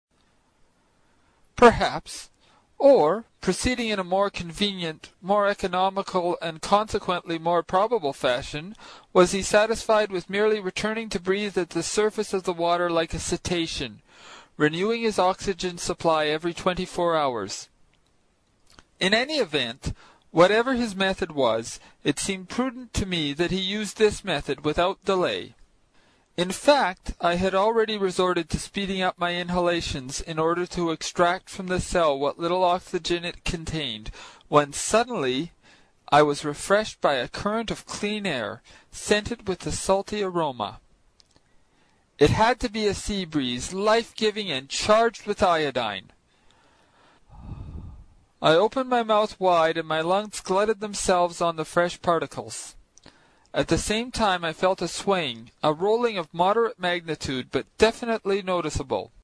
英语听书《海底两万里》第118期 第9章 尼德·兰的愤怒(3) 听力文件下载—在线英语听力室
在线英语听力室英语听书《海底两万里》第118期 第9章 尼德·兰的愤怒(3)的听力文件下载,《海底两万里》中英双语有声读物附MP3下载